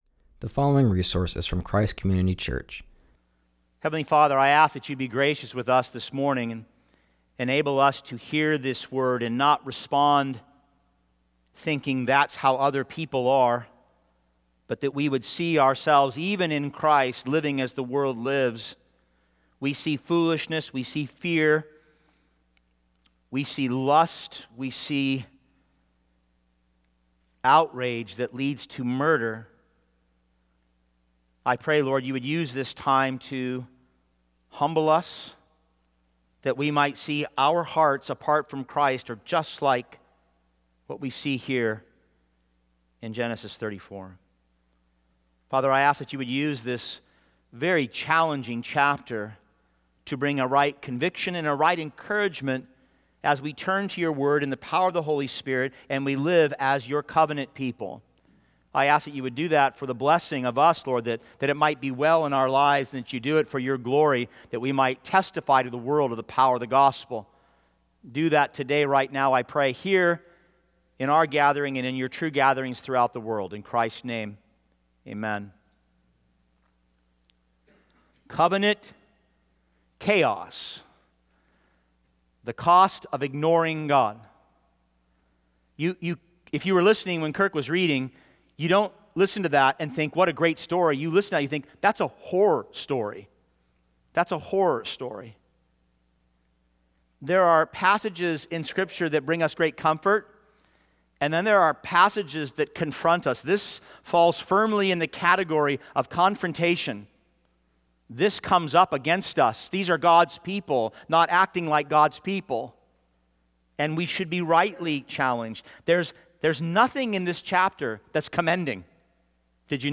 preaches from Genesis 34:1-34